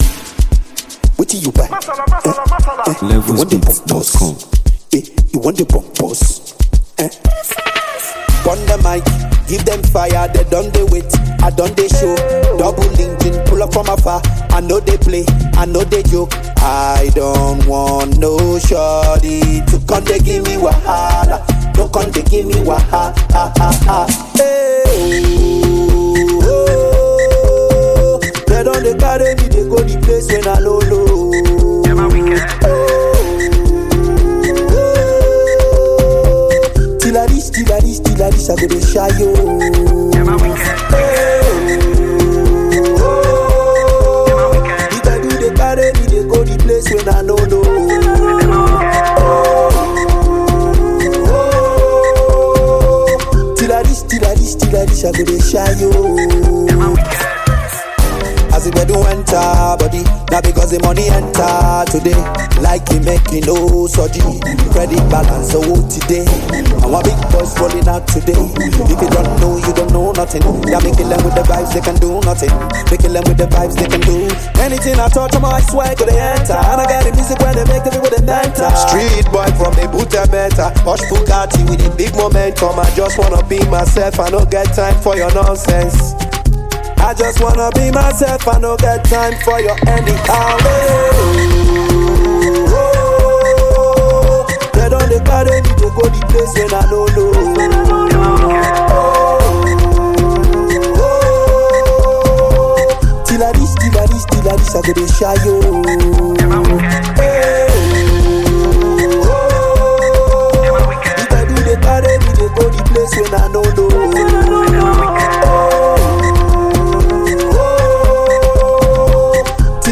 a versatile Nigerian Afrobeats and R&B singer
exceptional vocal range, smooth delivery
blends infectious rhythms with rich melodies